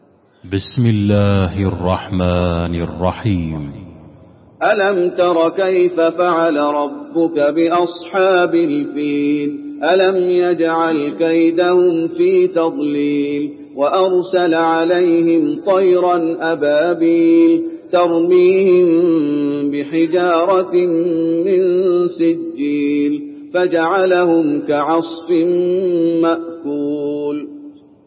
المكان: المسجد النبوي الفيل